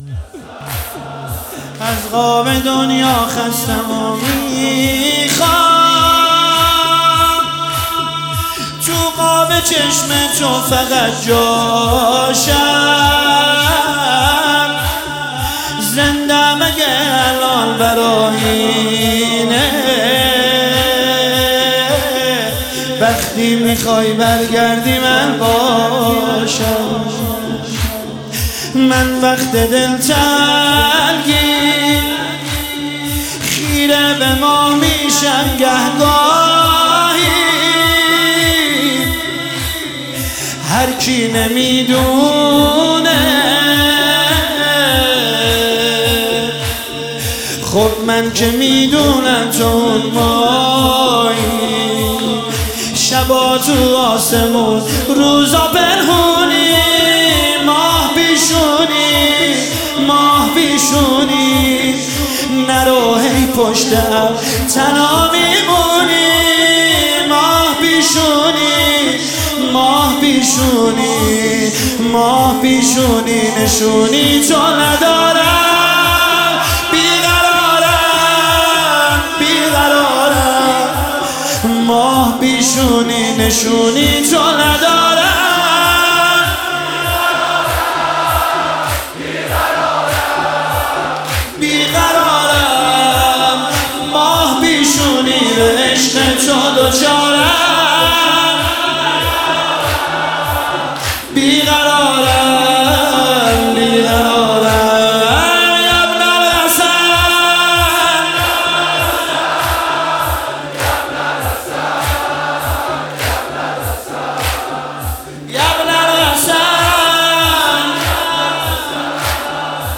مداحی زمینه شب 19 ماه رمضان شب قدر